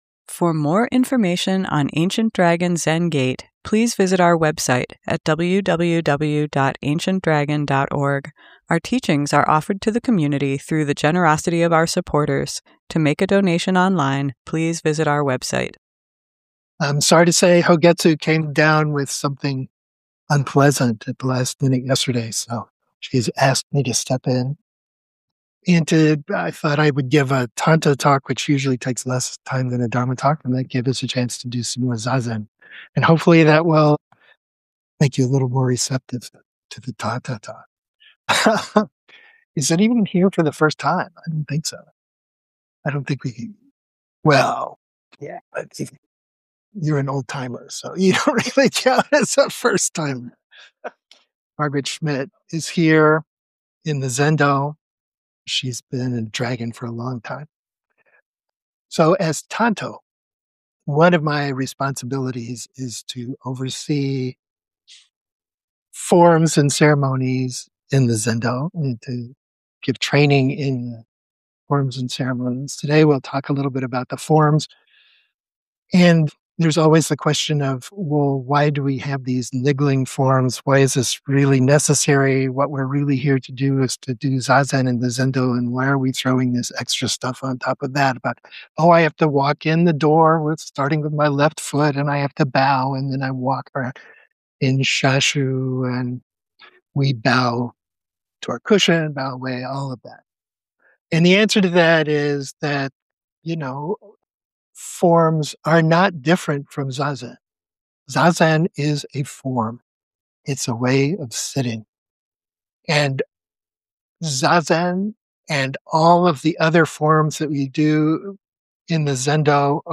Tanto Talk
ADZG Sunday Morning Dharma Talk